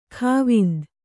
♪ khāvind